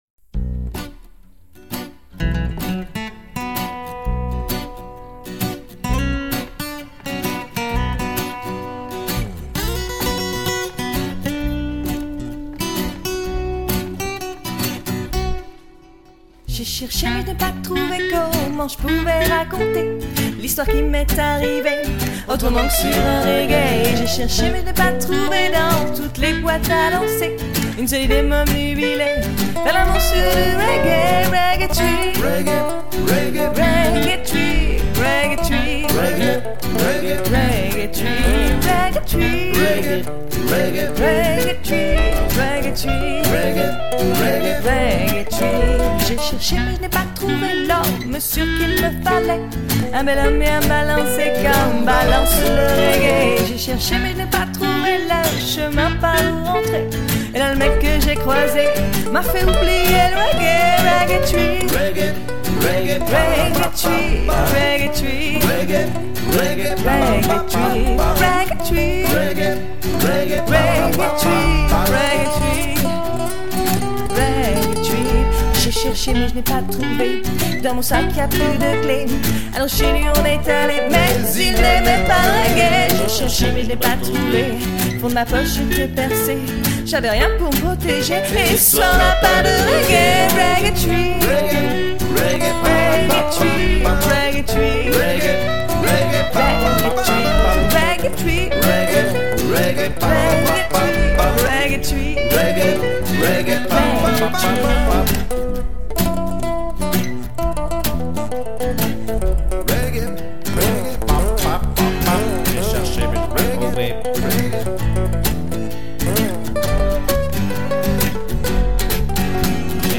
acoustik dynamiiik
chanson française décalée 2004